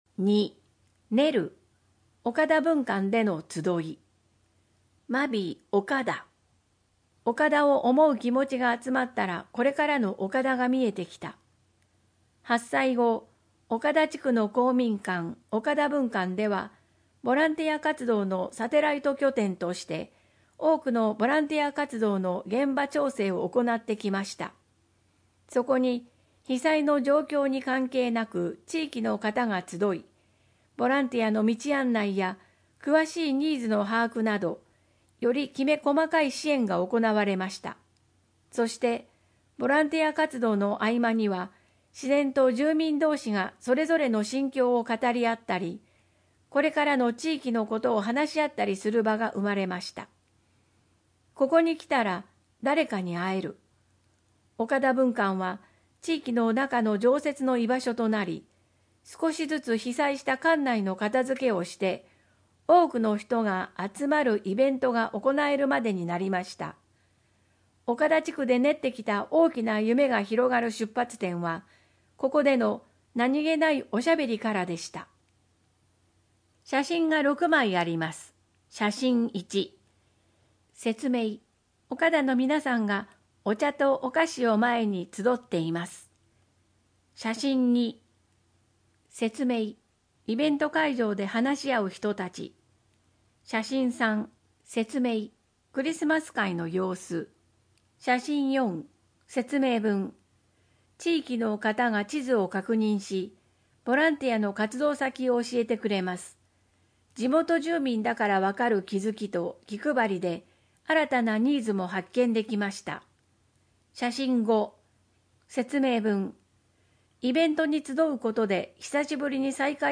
豪雨ニモマケズ（音訳版）